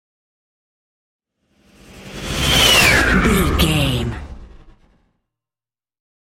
Airy whoosh large
Sound Effects
futuristic
whoosh
sci fi